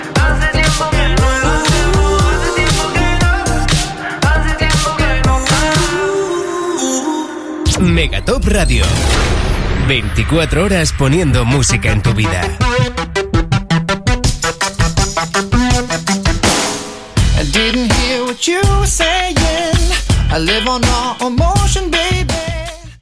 Tema musical i indicatiu de la ràdio